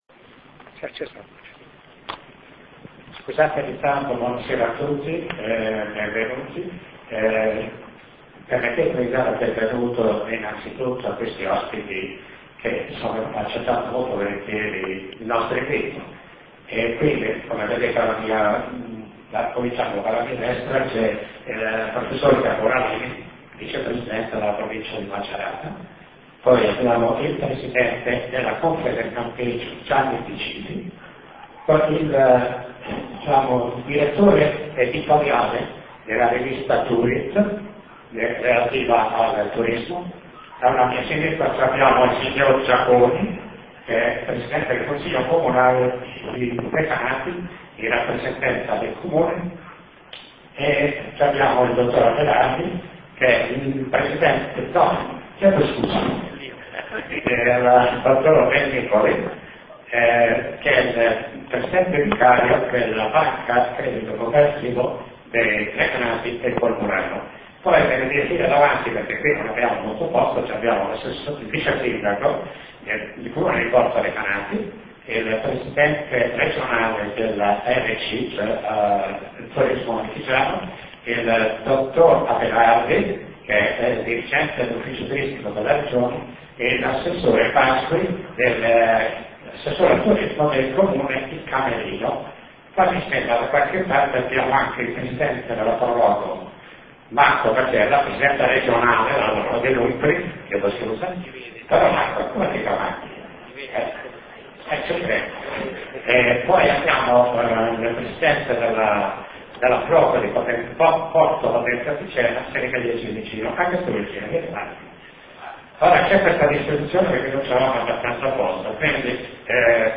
Camping Club Recanati Camping Club Recanati RADUNO NAZIONALE CAMPER in occasione del 25� anniversario di fondazione Recanati, 30 Maggio - 03 Giugno 2007 Registrazione audio della serata conclusiva